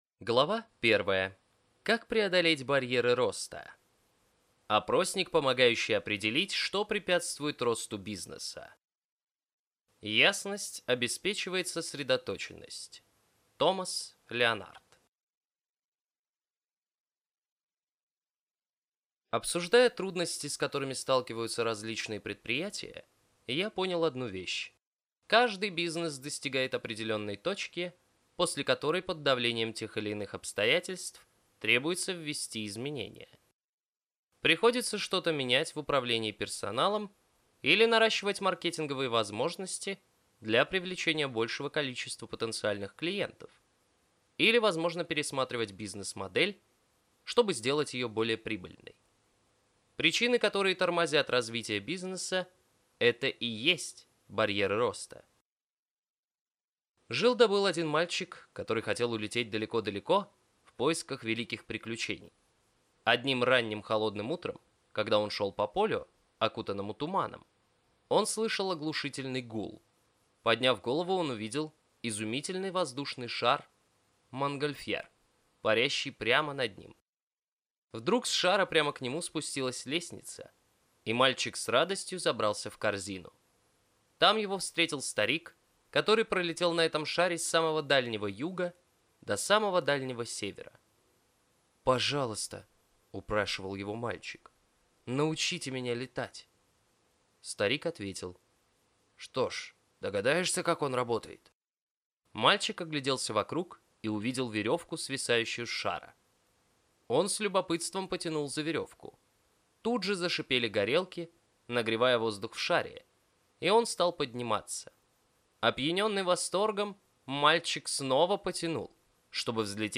Аудиокнига Как удвоить бизнес. Стратегии преодоления барьеров на пути к высокому росту, обороту и прибыли | Библиотека аудиокниг